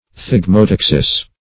Search Result for " thigmotaxis" : The Collaborative International Dictionary of English v.0.48: Thigmotaxis \Thig`mo*tax"is\, n. [NL., fr. Gr.